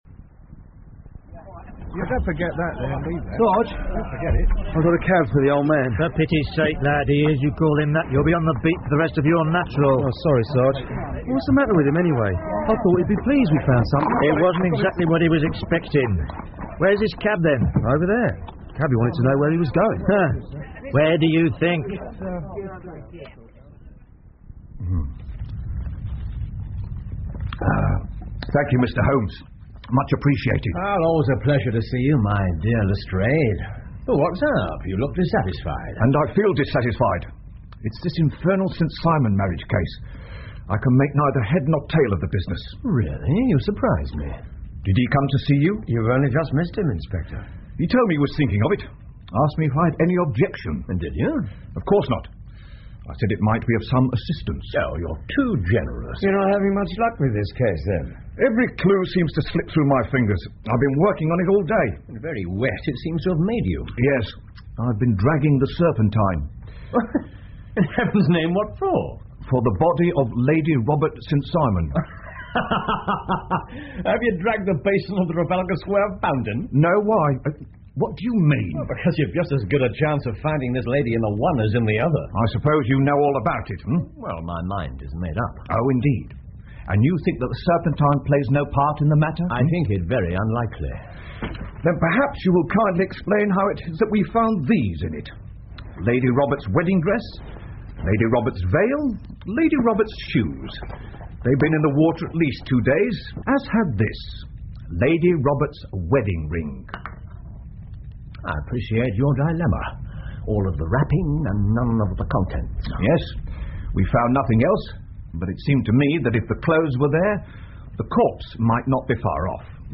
福尔摩斯广播剧 The Noble Bachelor 6 听力文件下载—在线英语听力室